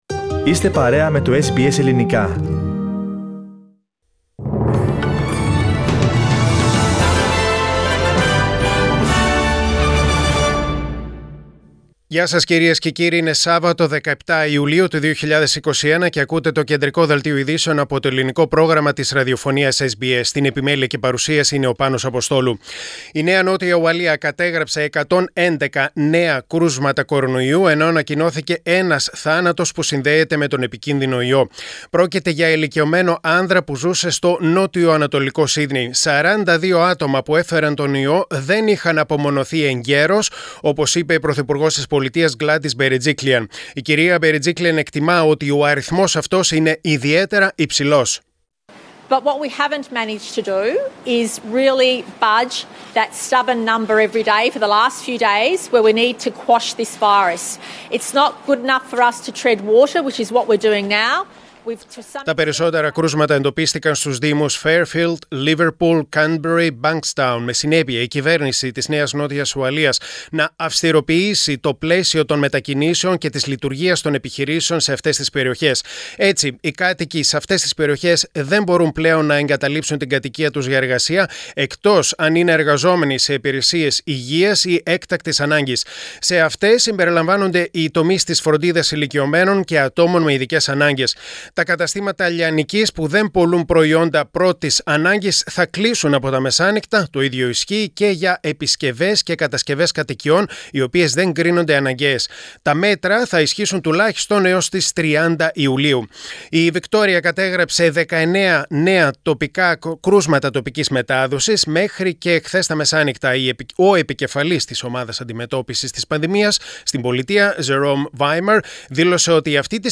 Press Play on the main photo and listen the News Bulletin (in Greek) Share